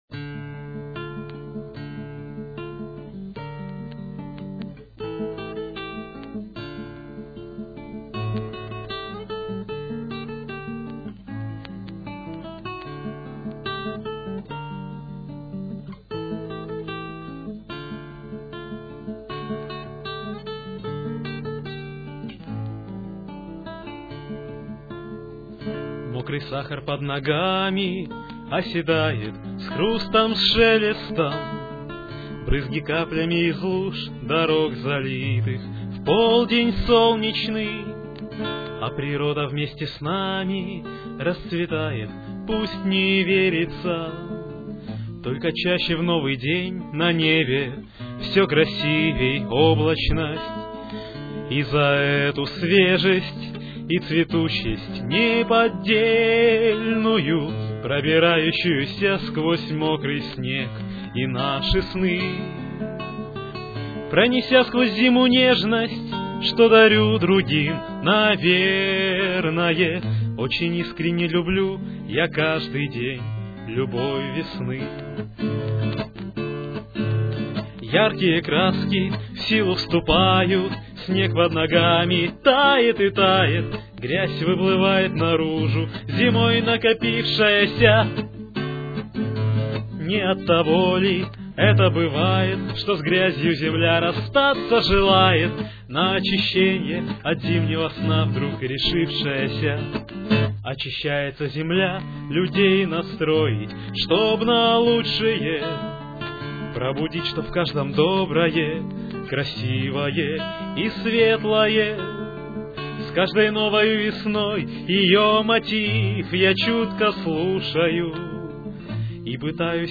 Гитара, вокал